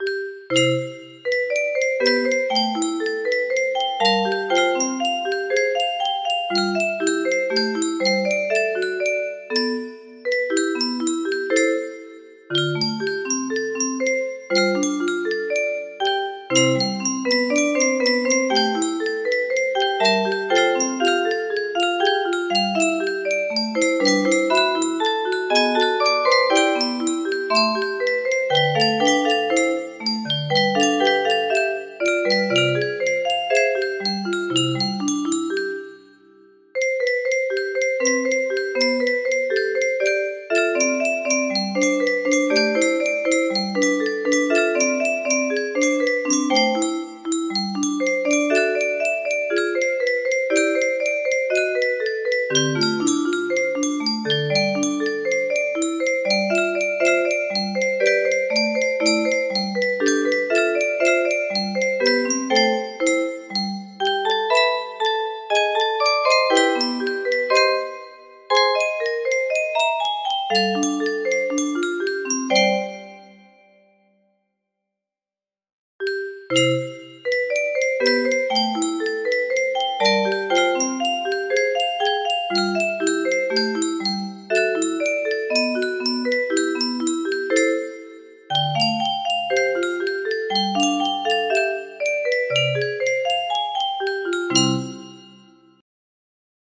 Starlight Isle music box melody